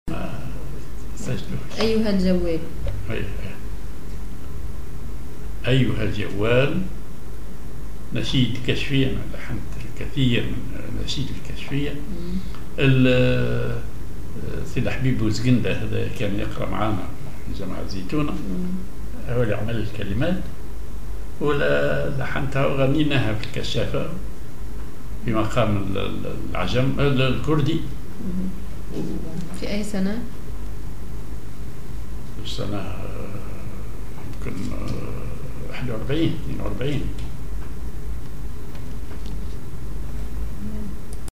Maqam ar كردي
genre نشيد